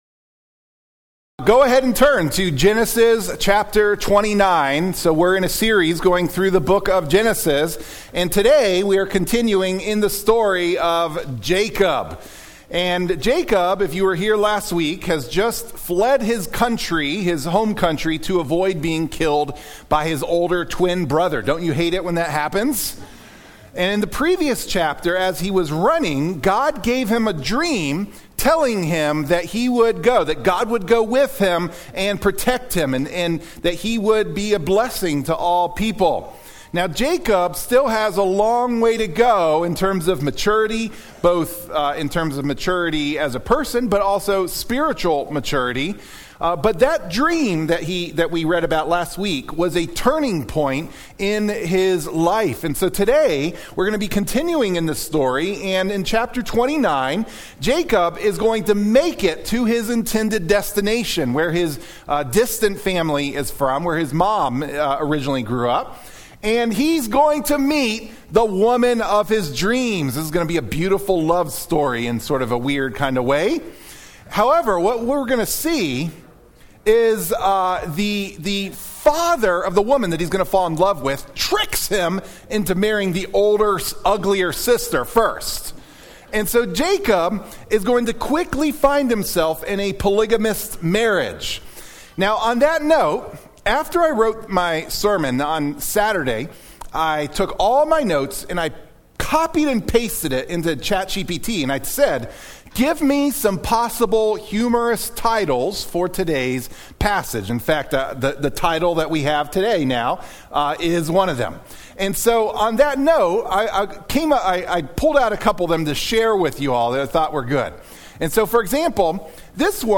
Sermon Clip The message notes for the sermon can be downloaded by clicking on the “save” button.